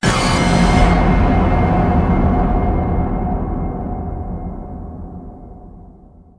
scpcb-godot/SFX/Horror/Horror14.ogg at master
Horror14.ogg